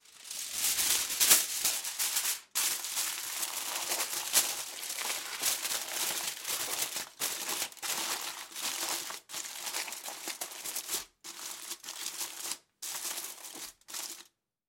Звуки фольги - скачать и слушать онлайн бесплатно в mp3
Из коробки с фольгой вынимаем коробку